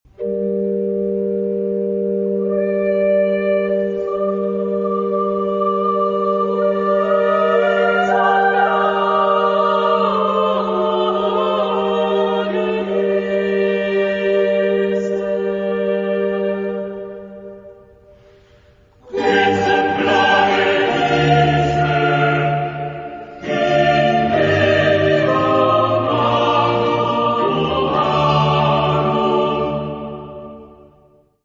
Género/Estilo/Forma: Cantata ; Sagrado
Carácter de la pieza : piadoso ; ferviente
Tipo de formación coral: SSATB  (5 voces Coro mixto )
Solistas : SSATB  (5 solista(s) )
Instrumentación: Ensamble instrumental  (4 partes instrumentales)
Instrumentos: Bajo Continuo ; Violín (2) ; Violone
Tonalidad : sol menor
por Dresdner Kammerchor dirigido por Hans-Christoph Rademann